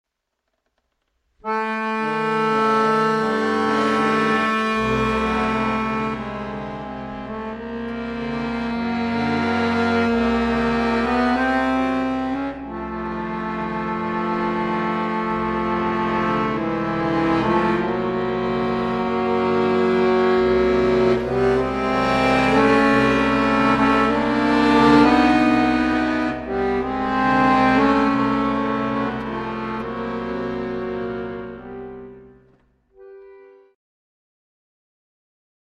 Preludio Moderato e Maestoso Assai